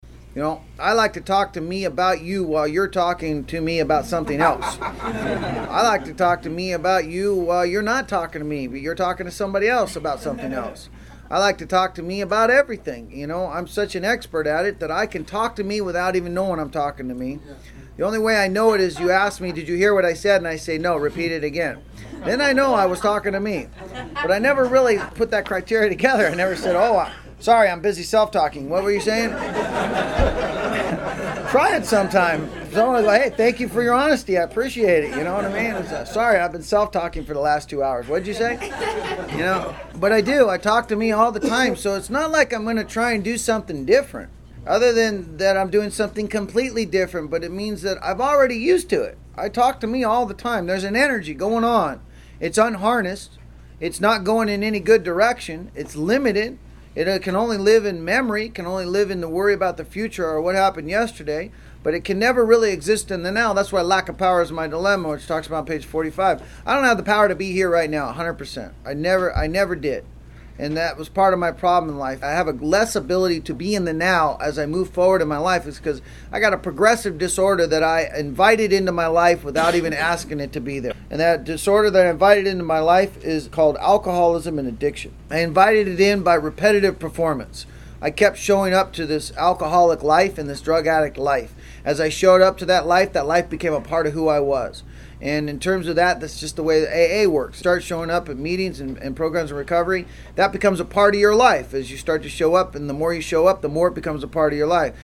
This audio archive is a compilation of many years of lecturing.